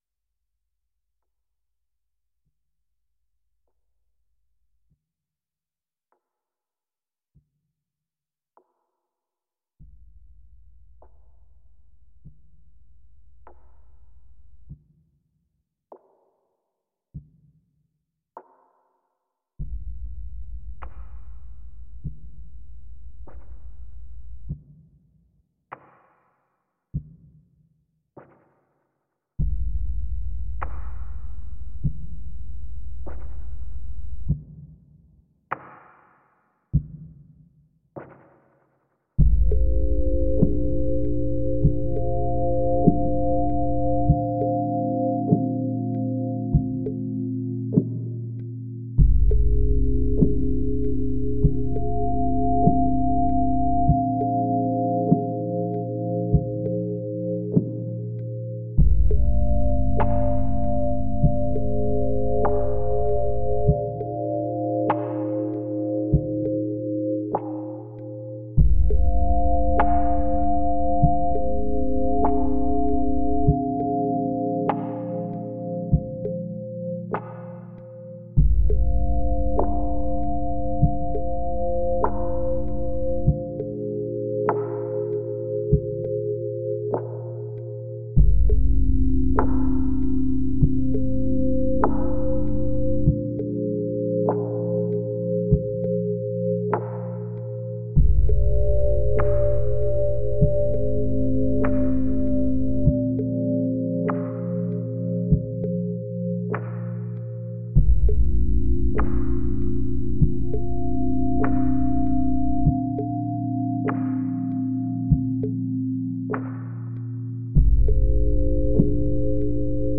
Out with the drones, in with the lush pads and synth lines.